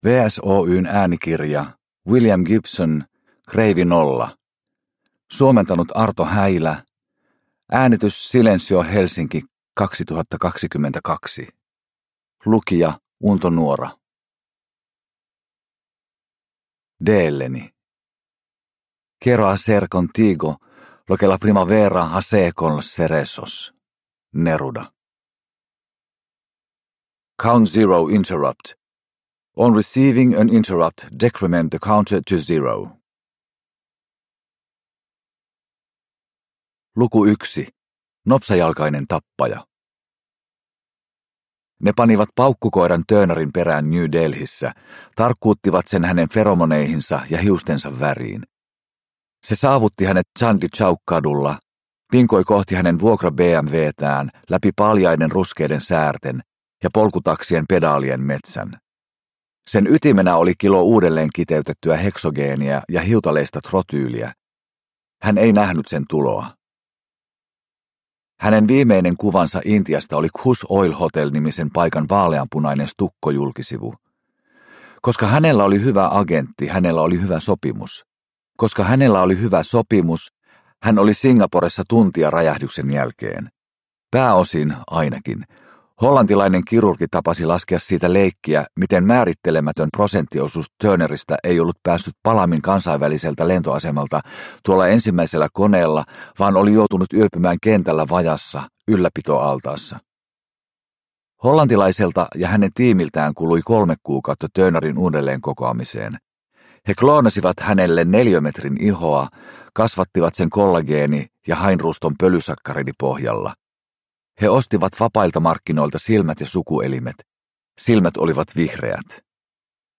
Kreivi Nolla – Ljudbok – Laddas ner